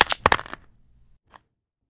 BrokeWood06.ogg